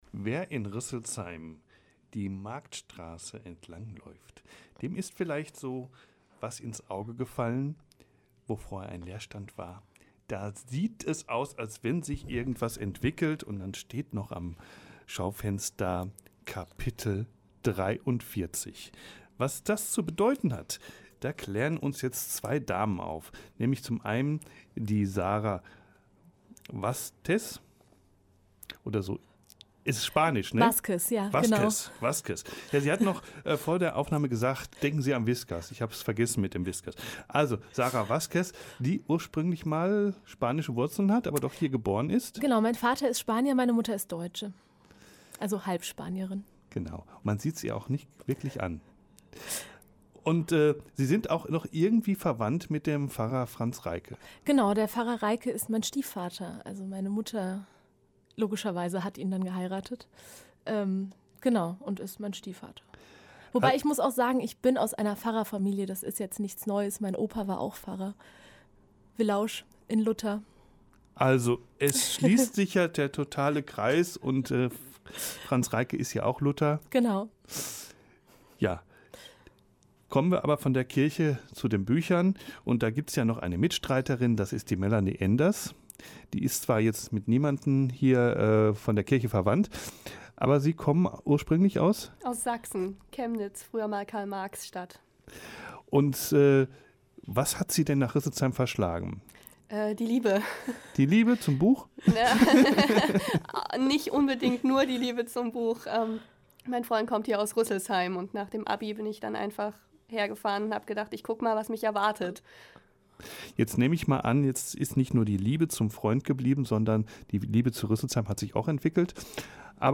Interview von Radio-Rüsselsheim
Hier das verpasste Interview von Radio-Rüsselsheim